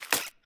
Sfx_creature_babypenguin_hop_07.ogg